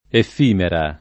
effimera [ eff & mera ]